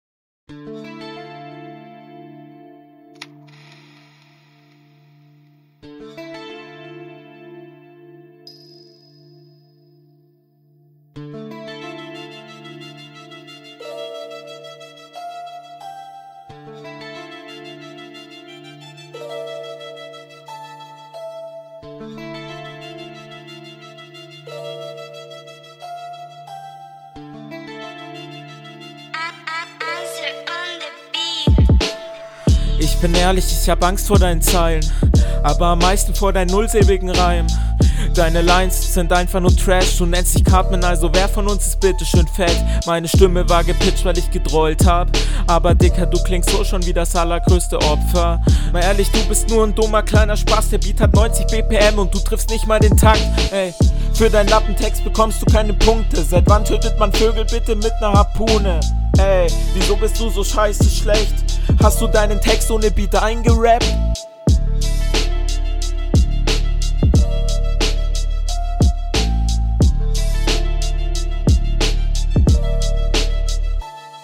Der Flow ist bei dir schon deutlich sicherer als beim Gegner und das Beste an …
Flow find ich cool auch wenn du meiner Meinung Nach gerne noch mehr aus dir …